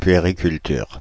Ääntäminen
Paris: IPA: [pɥe.ʁi.kyl.tyʁ]